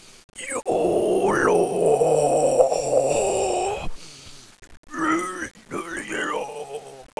growl.wav